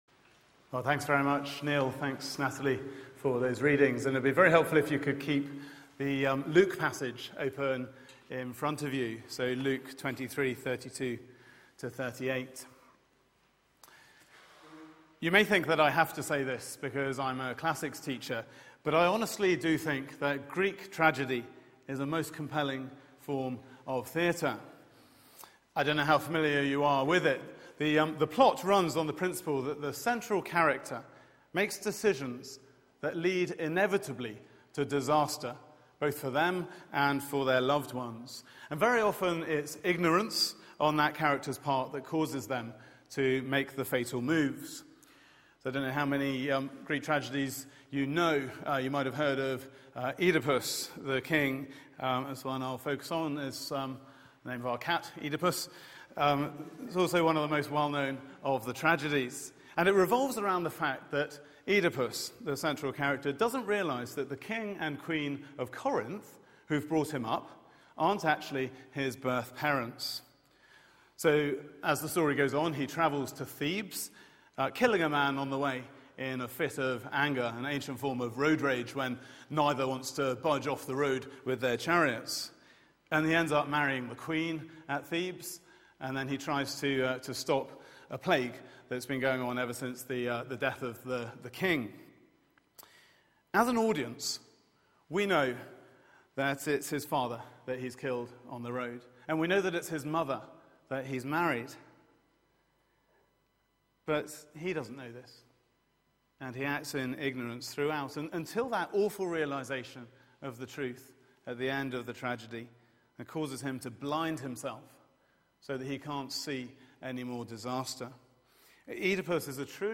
Media for 6:30pm Service on Sun 30th Mar 2014
Passage: Luke 23:32-38, Series: Christ's final day Theme: Father, forgive Sermon